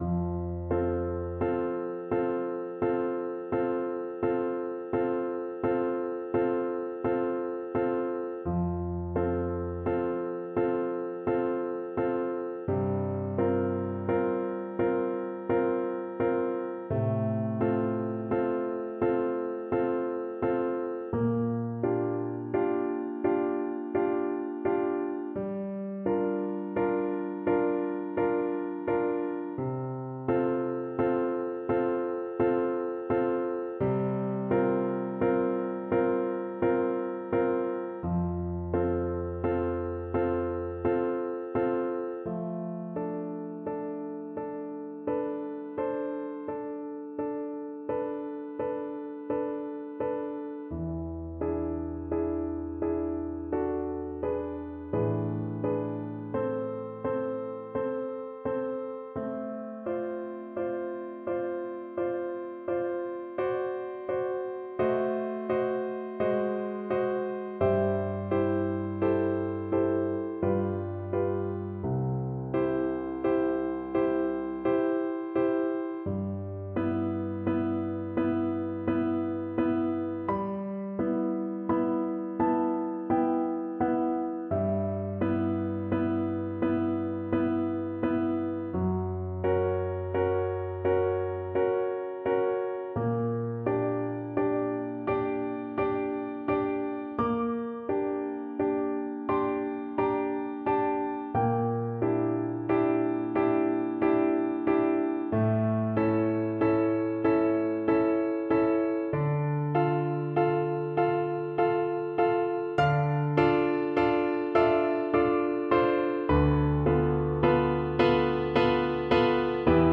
3/4 (View more 3/4 Music)
Andante (=c.60)
Classical (View more Classical French Horn Music)